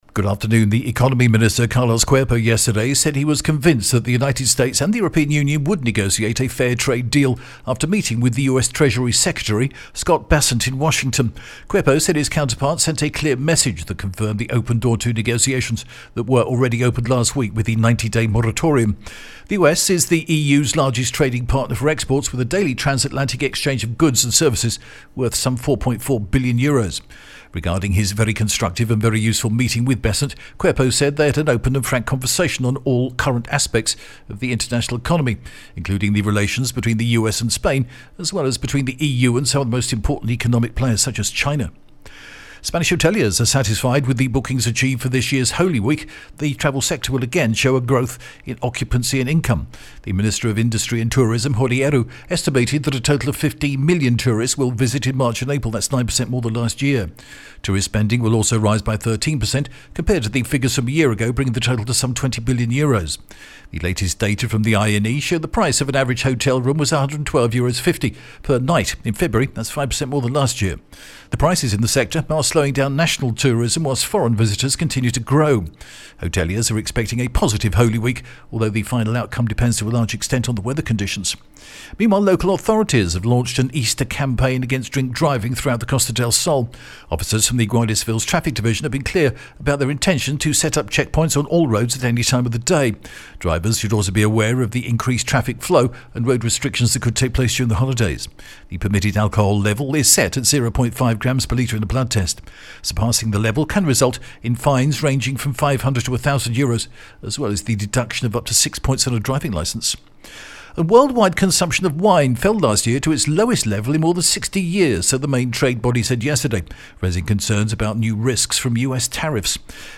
The latest Spanish news headlines in English: April 16th 2025